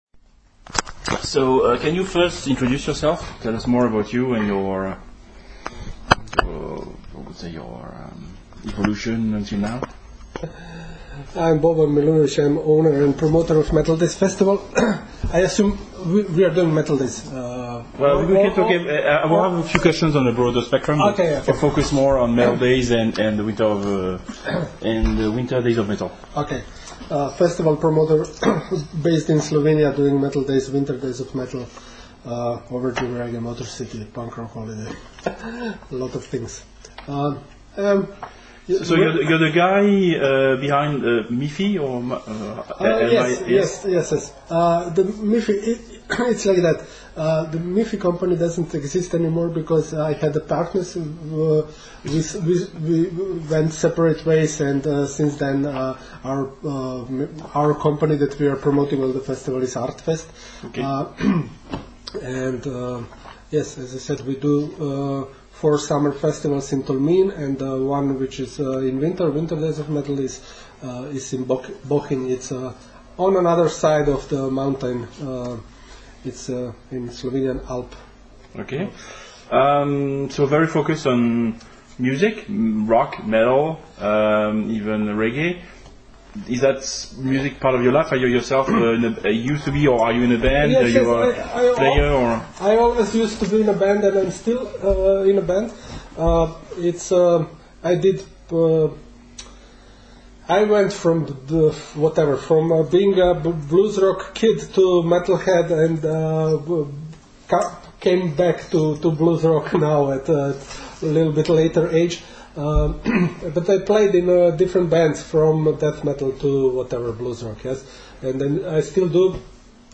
METAL DAYS 2018 (interview